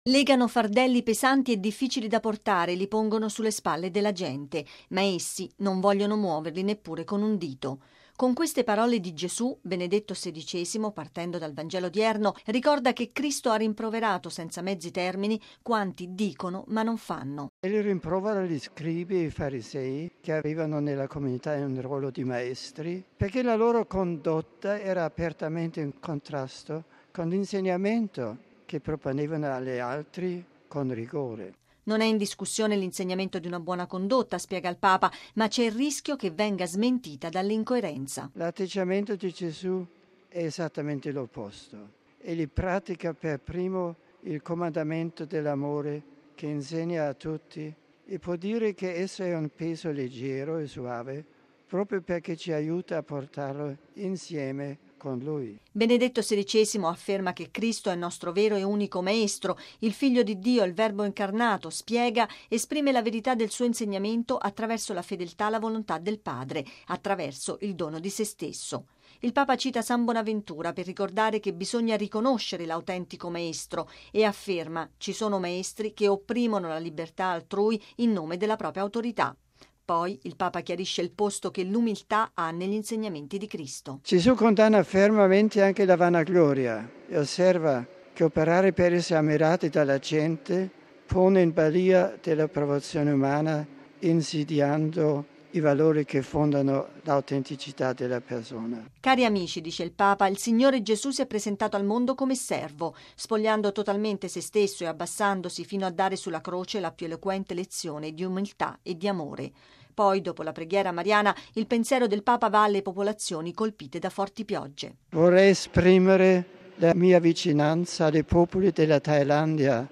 ◊   “La buona dottrina va accolta, ma rischia di essere smentita da una condotta incoerente”: sono parole del Papa che all’Angelus ha parlato di coerenza e verità di insegnamenti ricordando che Cristo “pratica per primo il comandamento dell’amore, che insegna a tutti”. Benedetto XVI, ha rivolto un pensiero a quanti in Thailandia e in Italia sono stati colpiti in questi giorni dalle alluvioni e ha invitato tutti a confidare in Maria per seguire il cammino del Vangelo, ricordando che si conclude domani il mese del Rosario.